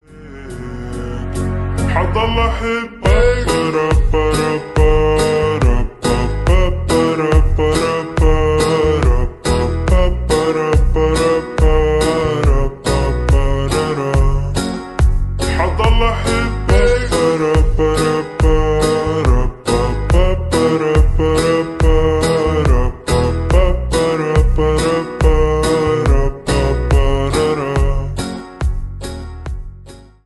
гитара
remix
восточные мотивы
грустные
спокойные
медленные
расслабляющие
арабские
slowed